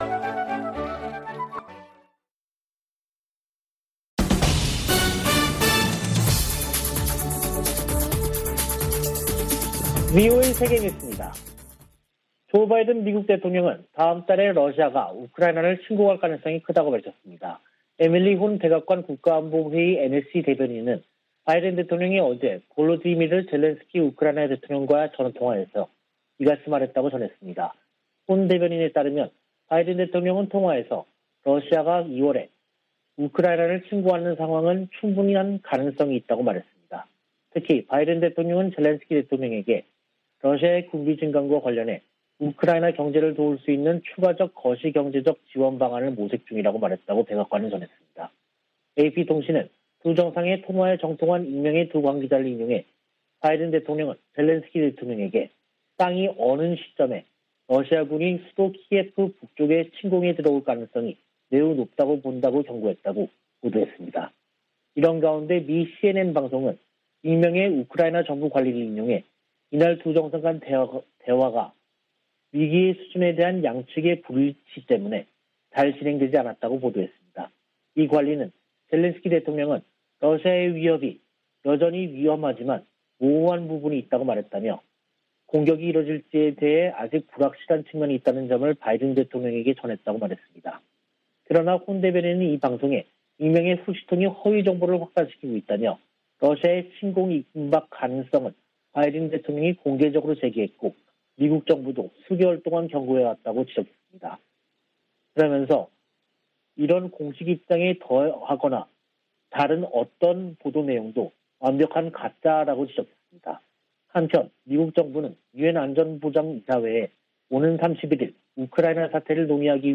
VOA 한국어 간판 뉴스 프로그램 '뉴스 투데이', 2022년 1월 28일 3부 방송입니다. 북한이 27일의 지대지 전술유도탄 시험발사와 지난 25일의 장거리 순항미사일 시험발사에 각각 성공했다고 28일 공개했습니다. 미 국무부는 외교 우선 대북 접근법을 확인하면서도, 도발하면 대가를 치르게 하겠다는 의지를 분명히 했습니다. 미 국방부는 북한의 불안정한 행동을 주목하고 있다며 잇따른 미사일 발사를 ‘공격’으로 규정했습니다.